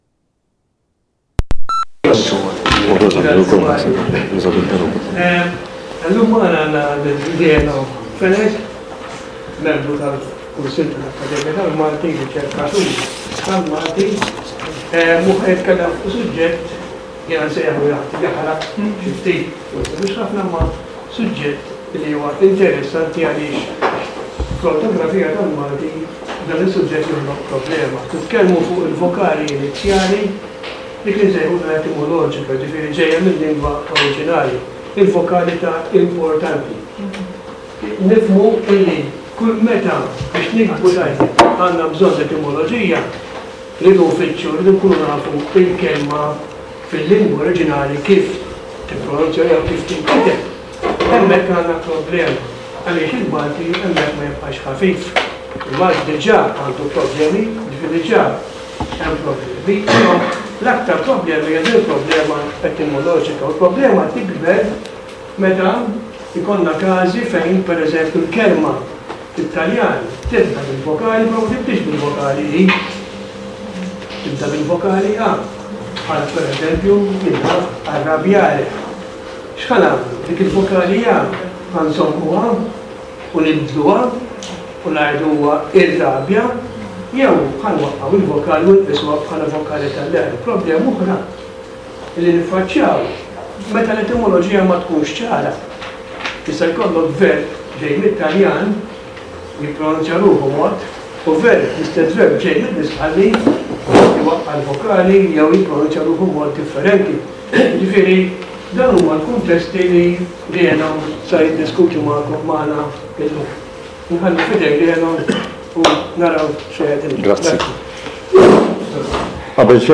Taħdita organizzata mill-Akkademja tal-Malti u d‑Dipartiment tal‑Kurrikulu u t‑Tagħlim Elettroniku